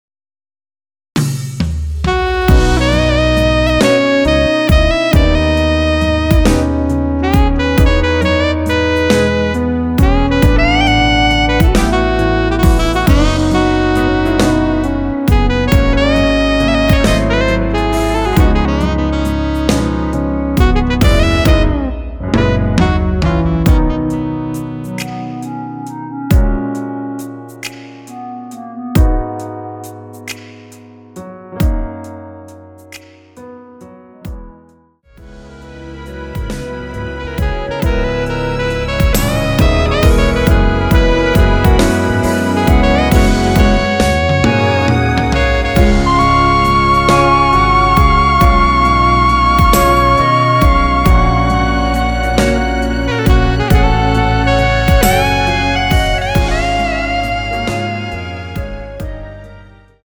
원키 멜로디 포함된 MR입니다.(미리듣기 확인)
앞부분30초, 뒷부분30초씩 편집해서 올려 드리고 있습니다.